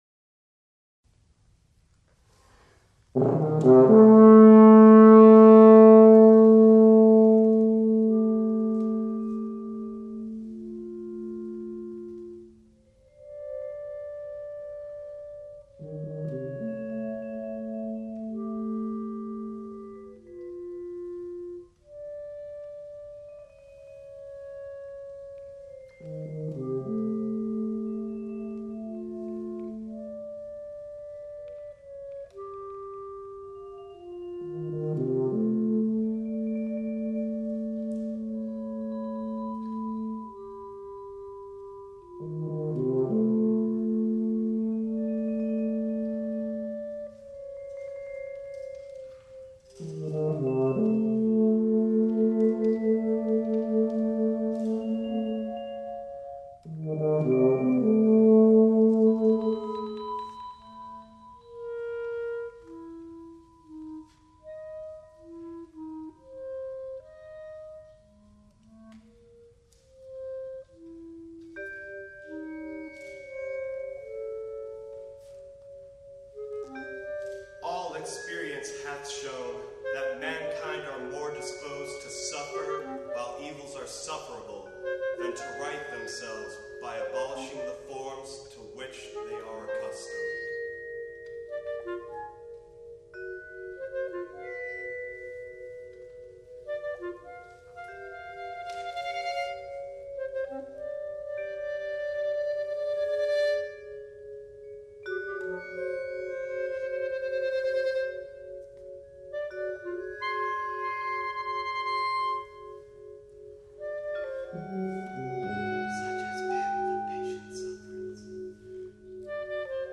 for male voice, clarinet, tuba, and vibraphone